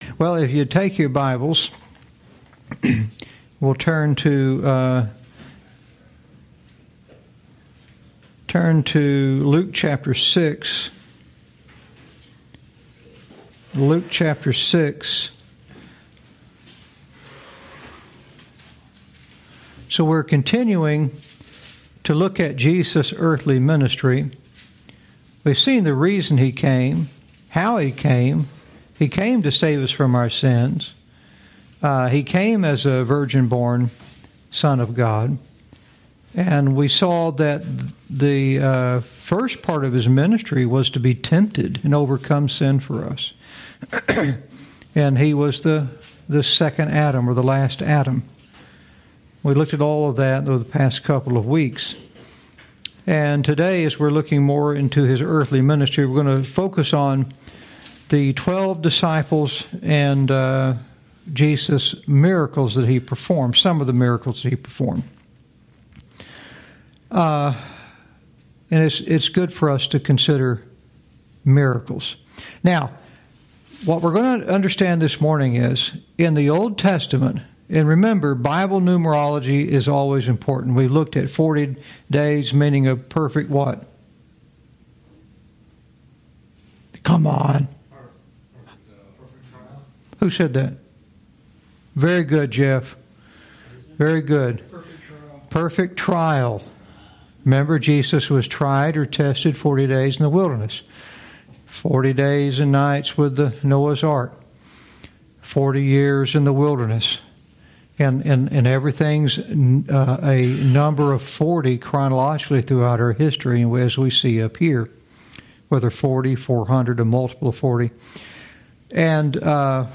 teaches verse by verse through the scriptures